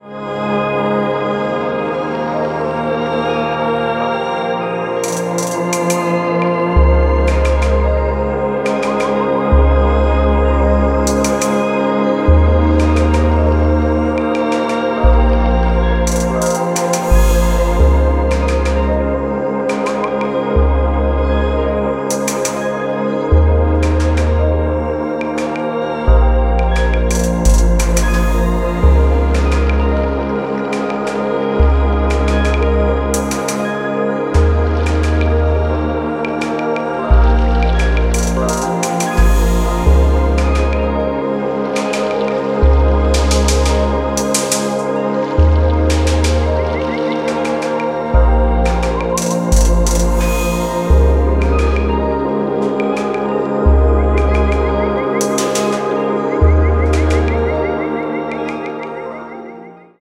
美しく幻想的なパッドとファンキーに跳ねたビートが繊細に交錯するアンビエント・エレクトロ、モダンIDMの傑作です。